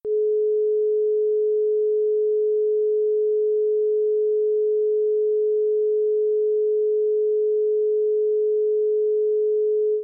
Sound example 17.7: The "Planck frequency," which is actually a pitch 135 octaves higher than this quite flat A (426 Hz). Because the Planck frequency is many orders of magnitude higher than the limits of human hearing, this example tranposes it into a comfortable range for hearing.